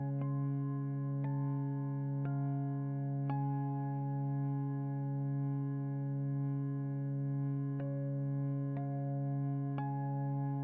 Descarga de Sonidos mp3 Gratis: zumbido hipnotico.
carillon_5.mp3